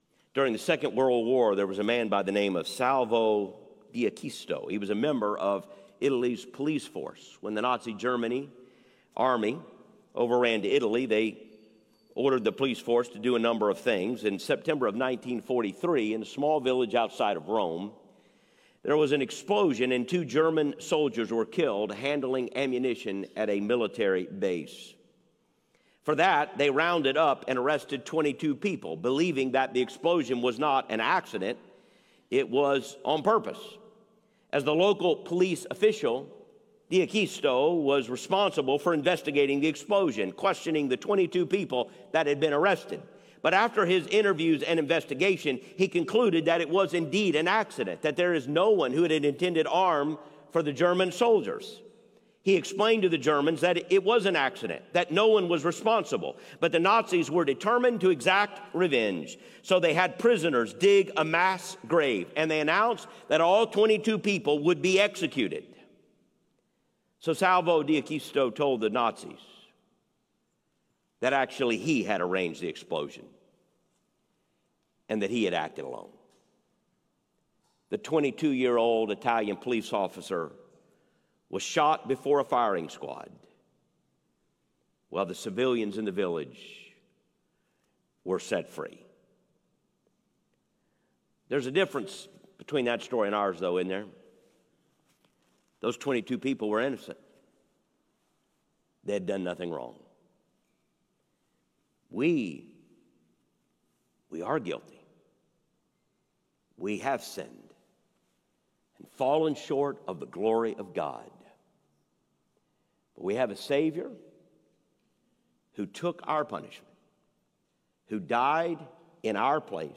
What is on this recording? Sermonic Example: